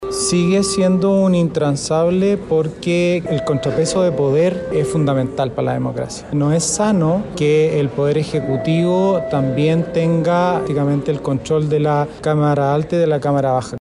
Pese a los diversos trascendidos, el jefe de bancada del PDG, Juan Marcelo Valenzuela, asegura que mantienen conversaciones “con todos los sectores”.